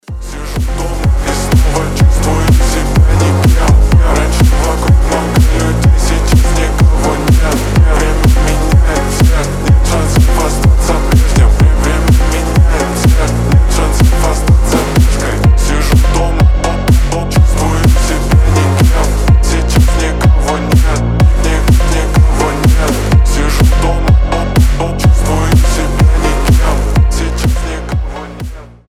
• Качество: 320, Stereo
громкие
басы
фонк